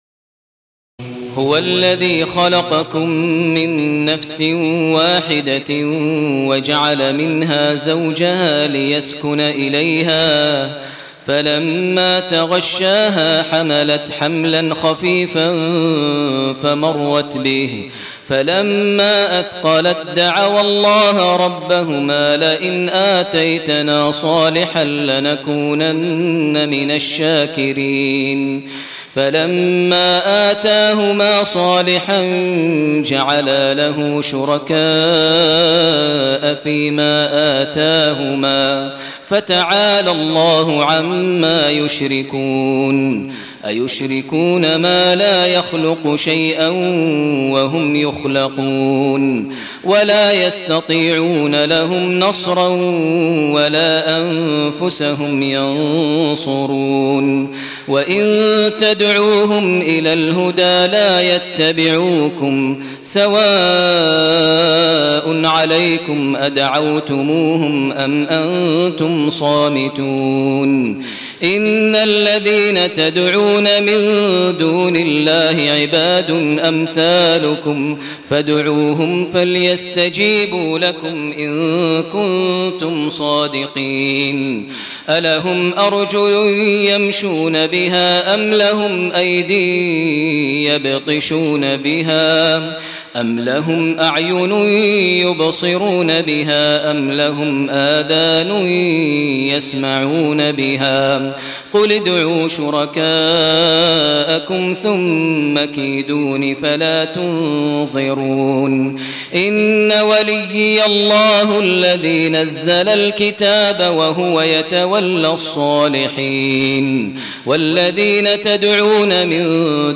تهجد ليلة 29 رمضان 1431هـ من سورتي الأعراف (189-206) و الأنفال (1-40) Tahajjud 29 st night Ramadan 1431H from Surah Al-A’raf and Al-Anfal > تراويح الحرم المكي عام 1431 🕋 > التراويح - تلاوات الحرمين